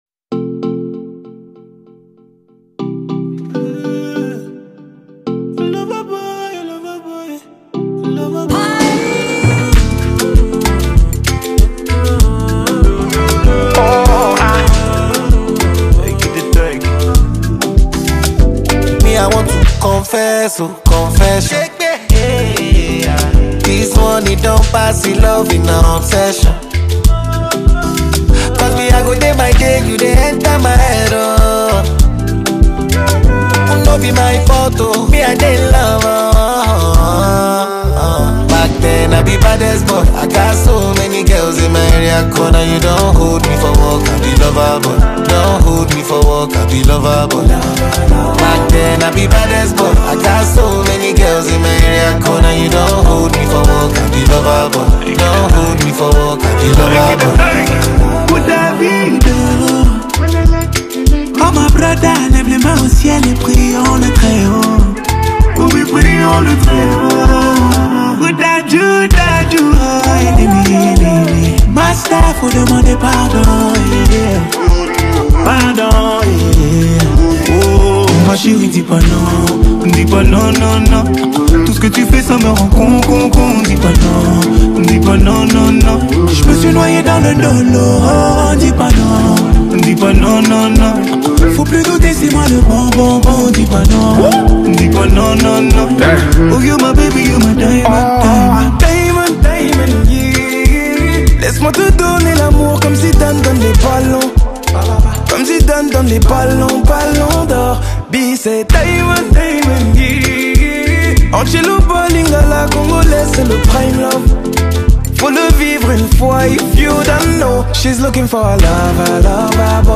• Genre: R&B